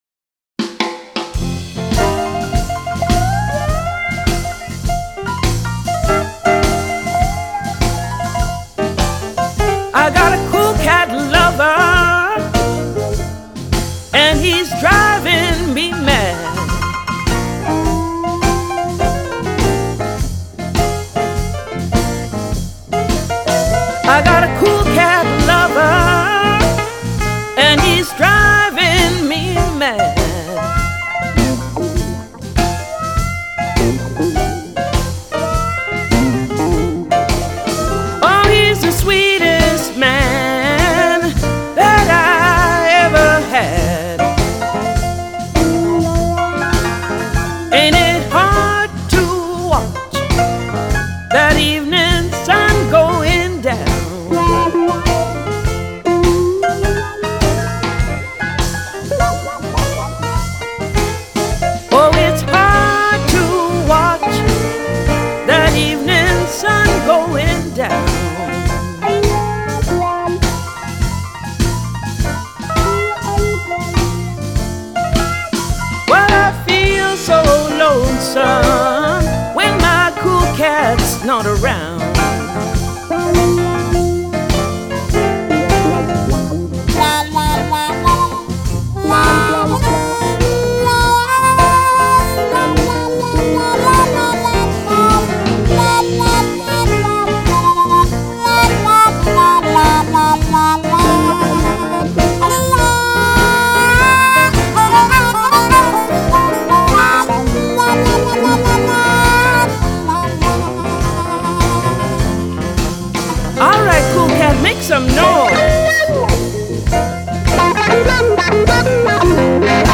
Тут неплохо мяукают!!